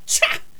princess_attack4.wav